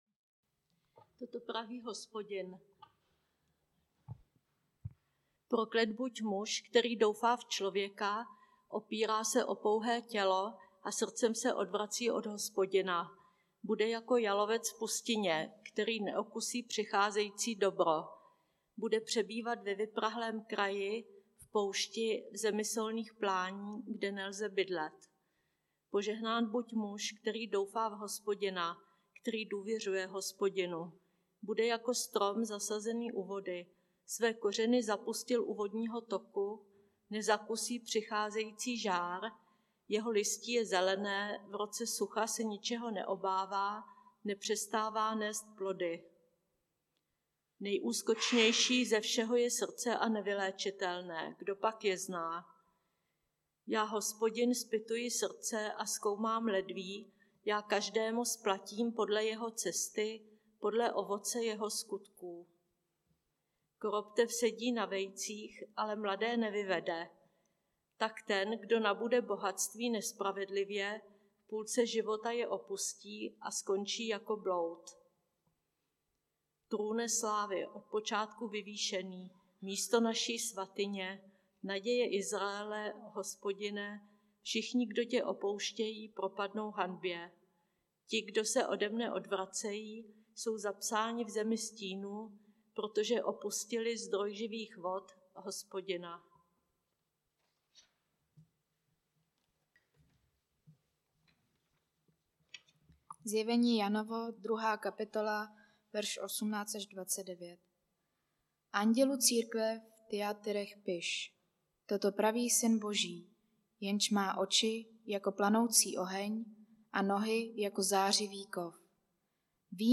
Nedělení kázání – 29.5.2022 List do Tyatir – CB Vinohrady